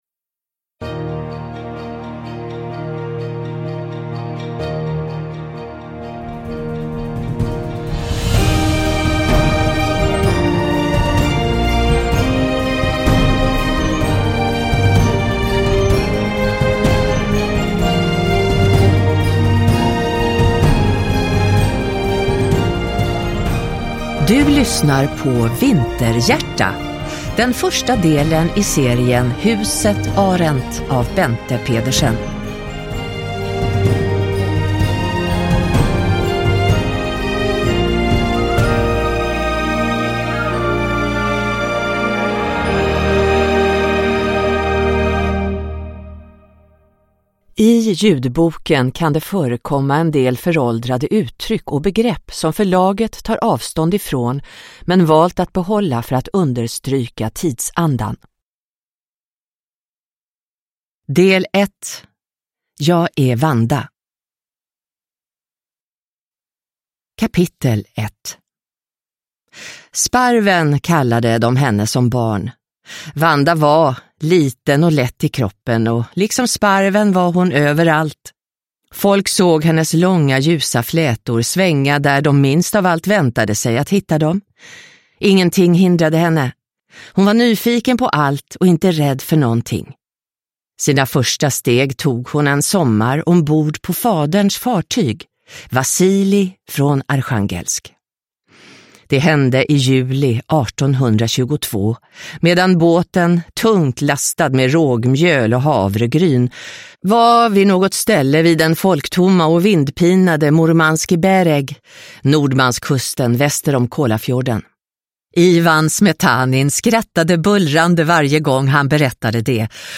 Vinterhjärta – Ljudbok